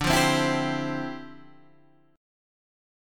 Dm11 chord